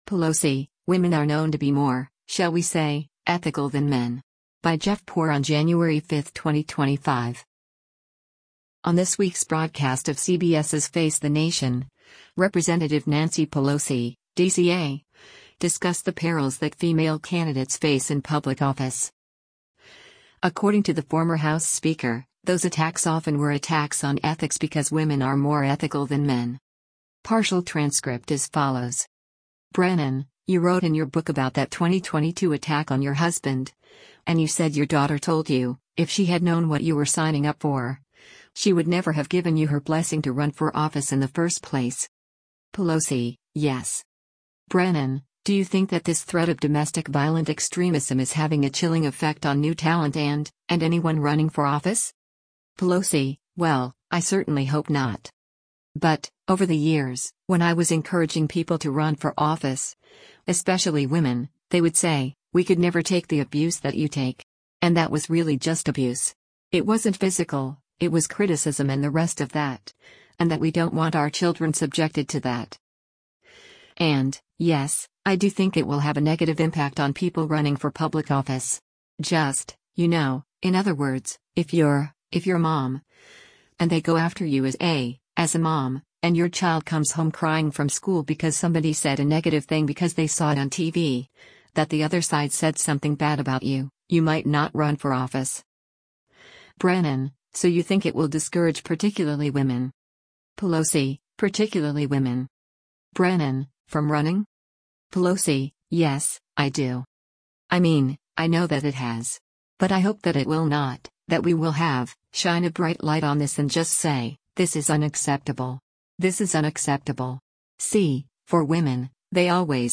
On this week’s broadcast of CBS’s “Face the Nation,” Rep. Nancy Pelosi (D-CA) discussed the perils that female candidates face in public office.